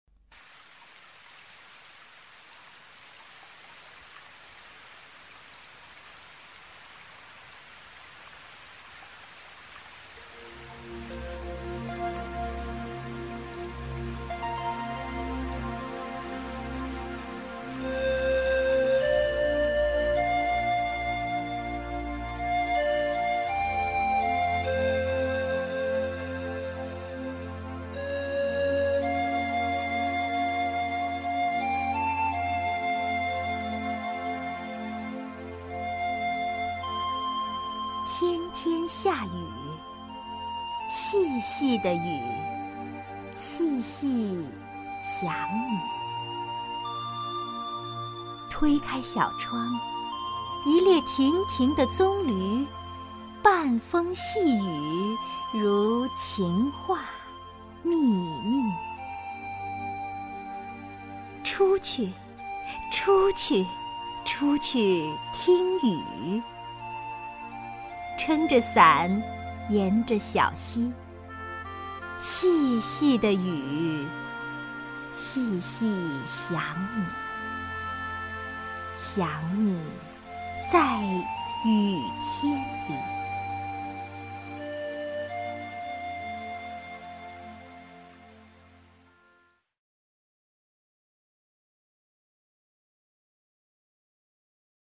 王雪纯朗诵：《雨天》(涂静怡)　/ 涂静怡